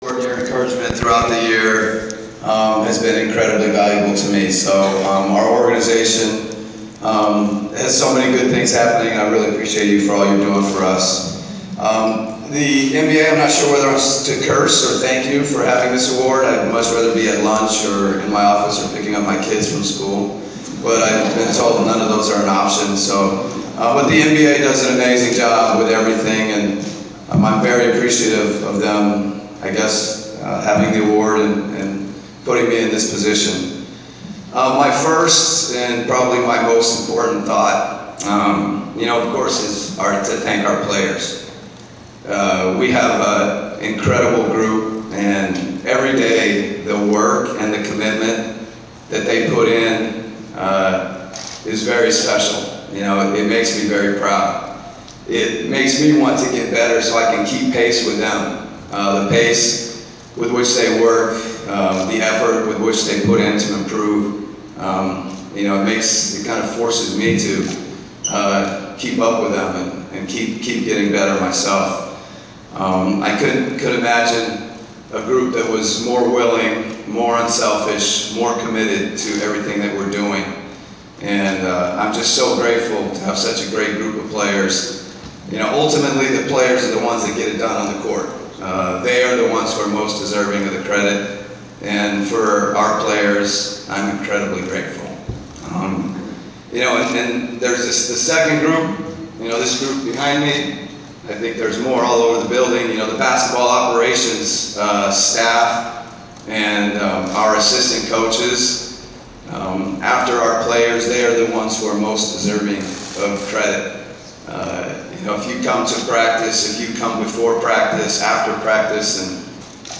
The audio of his acceptance speech is below:
bud-coach-of-the-year-acceptance.wav